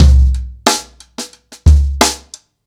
• 120 Bpm Drum Loop Sample C Key.wav
Free breakbeat sample - kick tuned to the C note.
120-bpm-drum-loop-sample-c-key-E7N.wav